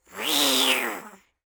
Fantasy Creatures Demo
goblin_rage_1.wav